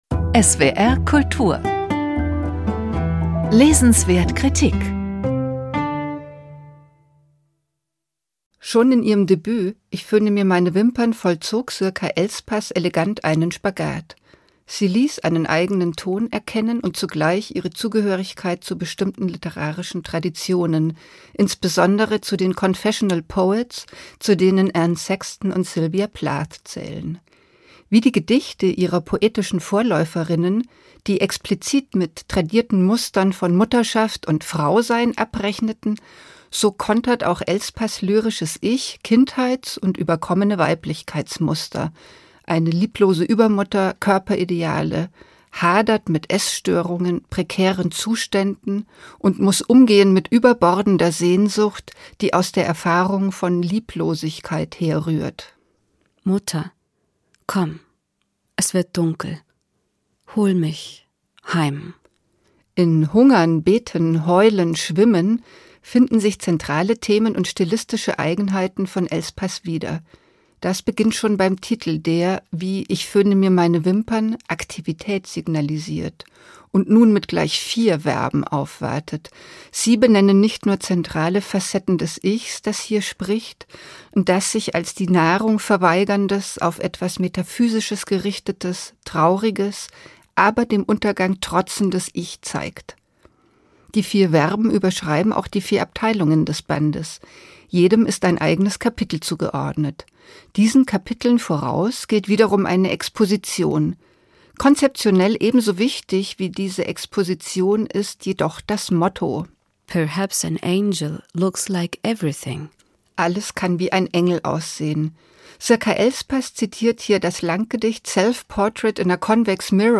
Rezension von